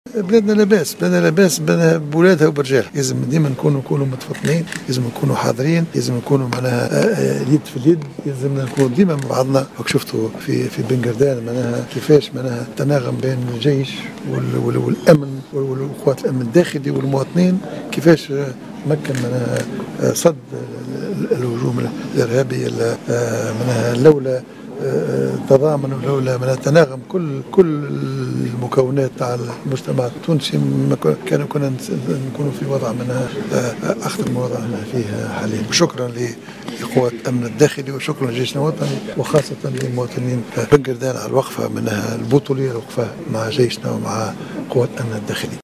"شكرا لمواطني بنقردان"، كان هذا أبرز ما قاله رئيس الحكومة الحبيب الصيد في تصريحات صحفية على اثر جلسة مشتركة أشرف عليها اليوم الأربعاء مع رئيس مجلس نوّاب الشعب محمد الناصر خُصّصت للنظر في رُزنامة العمل التشريعي.